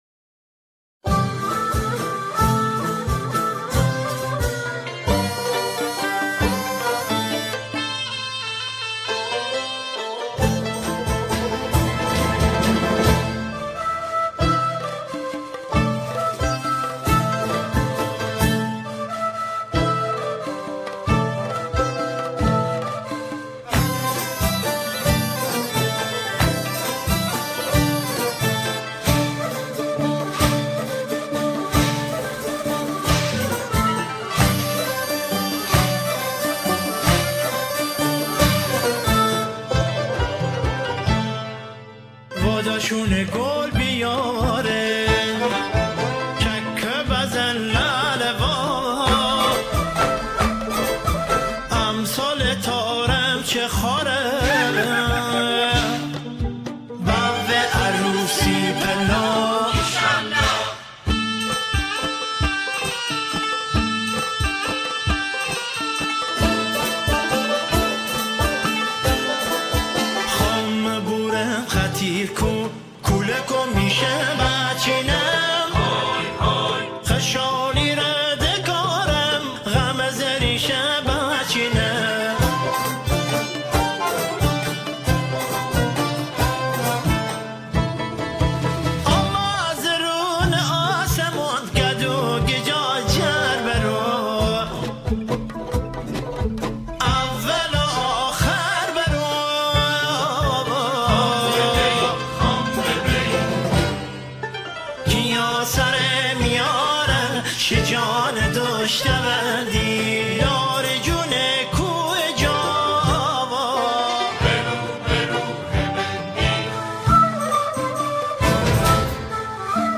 همخوانی شعری به گویش آذری
گروهی از همخوانان